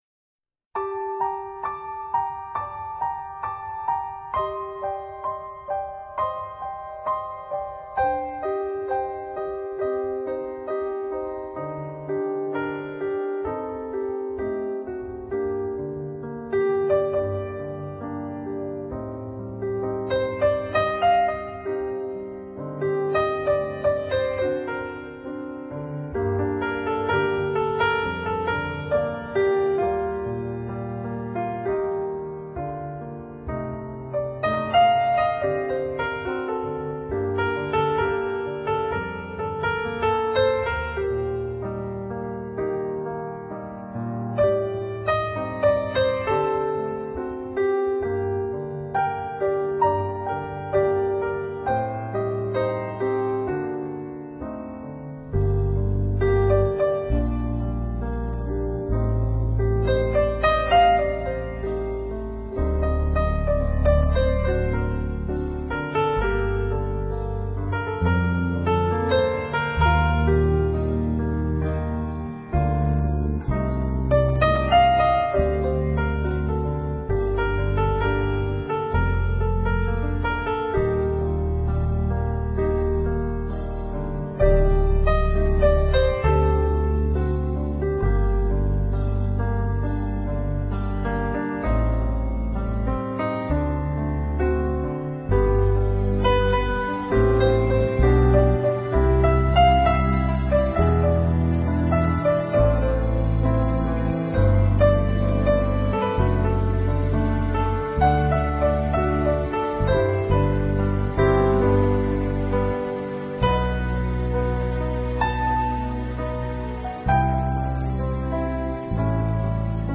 一張從心底觸動靈魂感官的鋼琴音樂 他的雙眼幾乎看不見，但是，他的音樂卻意外地澄清剔透…